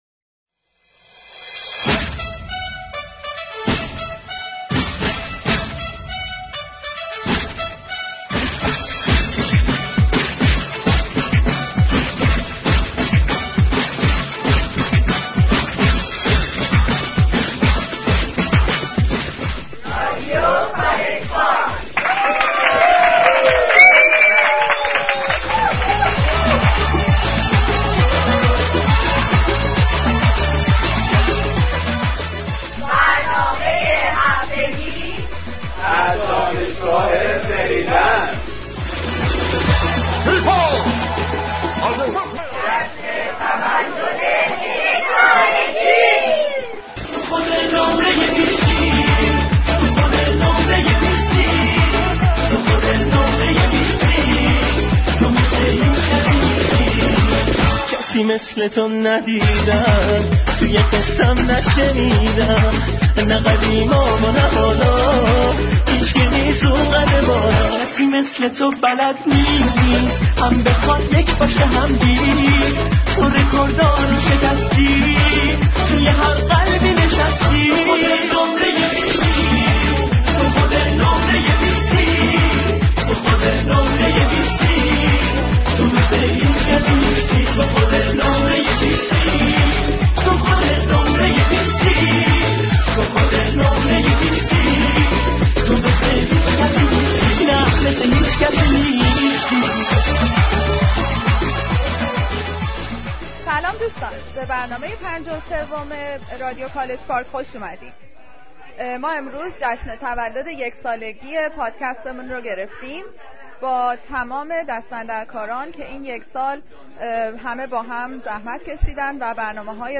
I just noticed that there was an annoying music in the background when some of the friends are speaking.